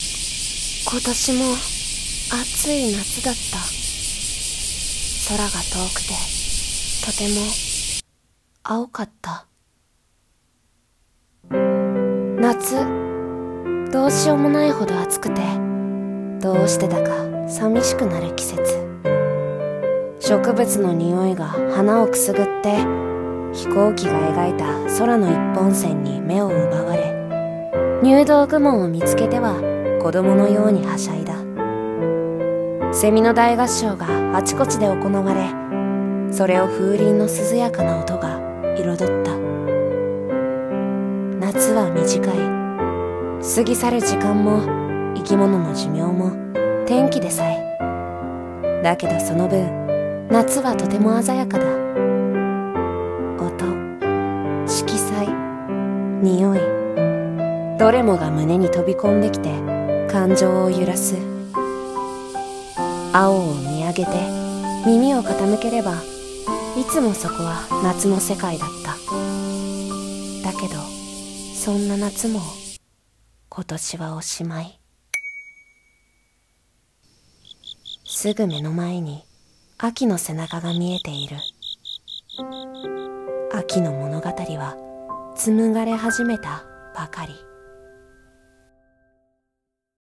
【一人声劇】空見上げ、耳澄ませ、